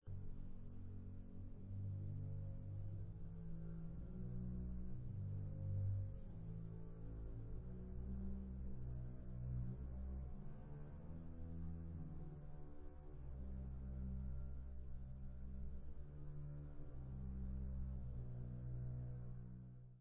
Sostenuto tranquillo ma cantabile
sledovat novinky v kategorii Vážná hudba